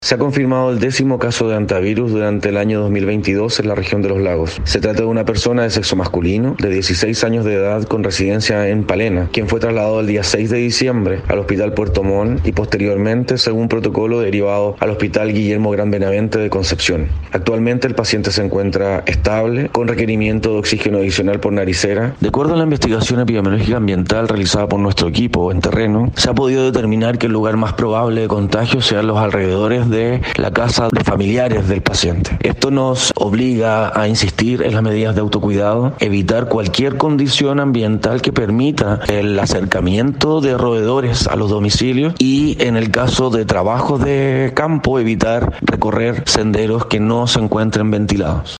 Así lo indicó el Seremi de Salud subrogante, Alex Oyarzo: